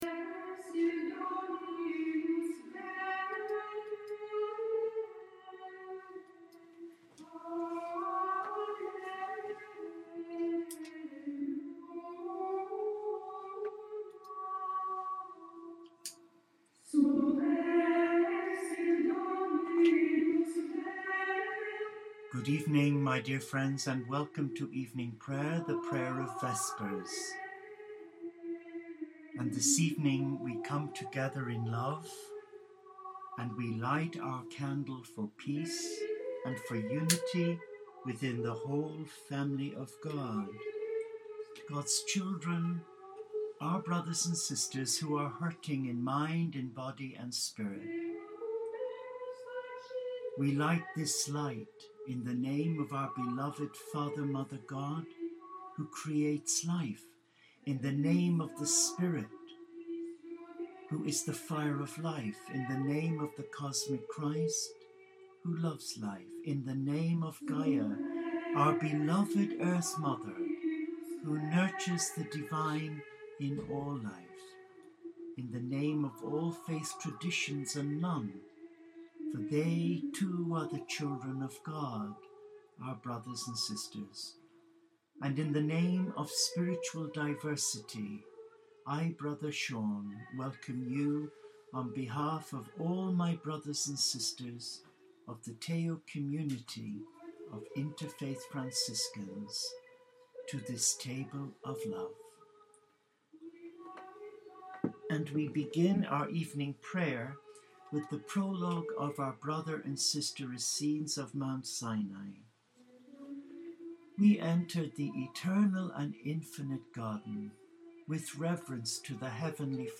This video is a live recording on Wednesday 9th April 2014. Our evening prayers are dedicated 4 'A Celebration of Light 4 HOPE, unity and peace in the world’